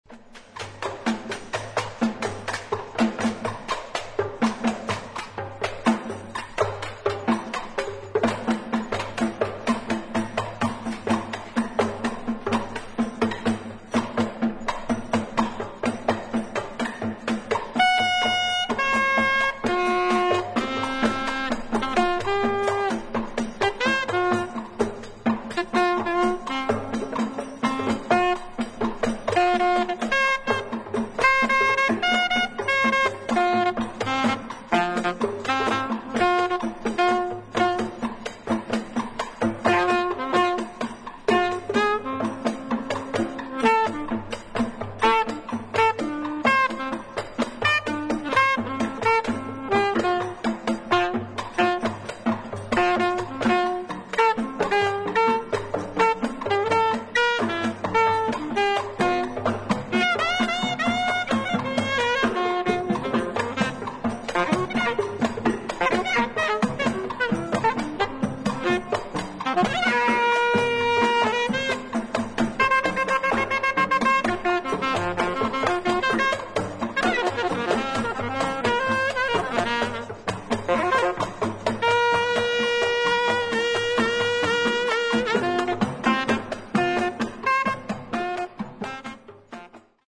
ドラムと民族的な打楽器によるダンサブルなリズム・ワークが冴える